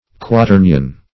Quaternion \Qua*ter"ni*on\, n. [L. quaternio, fr. quaterni four